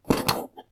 Wooden_Crate_Close.ogg